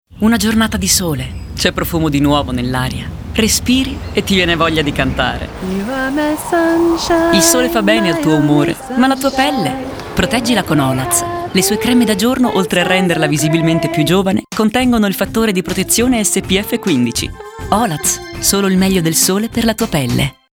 COMMERCIALS